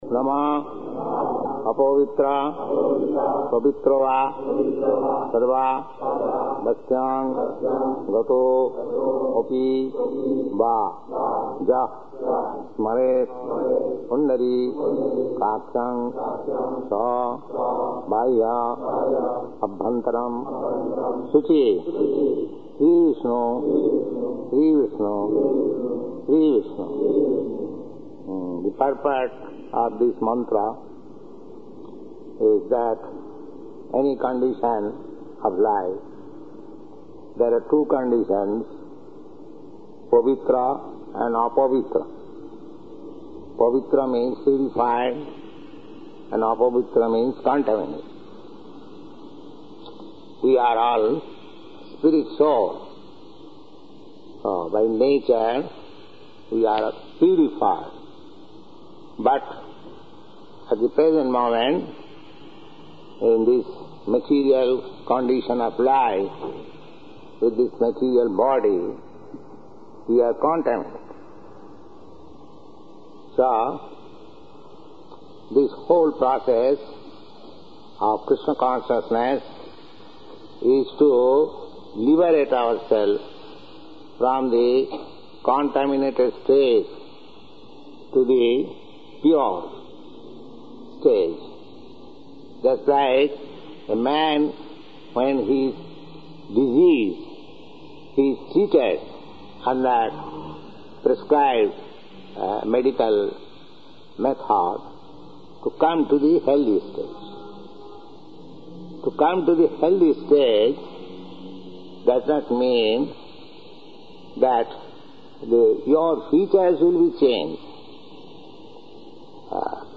Type: Initiation
Location: Los Angeles